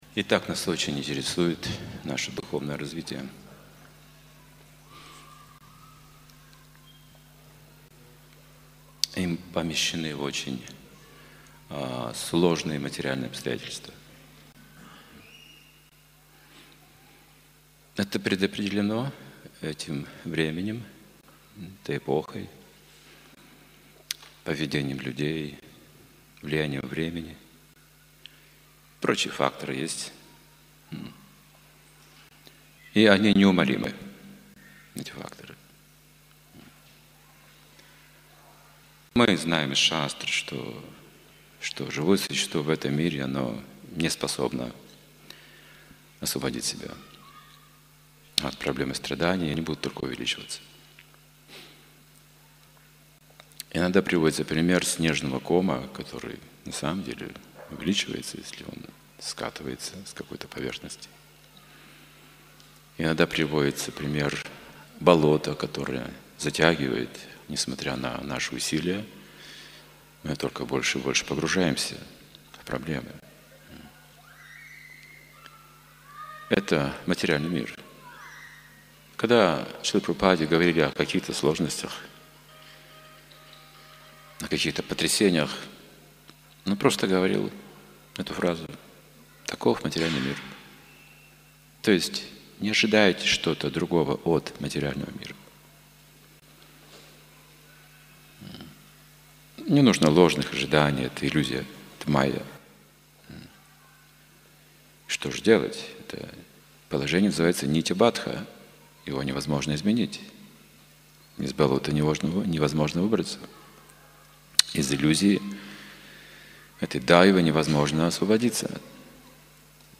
Лекции и книги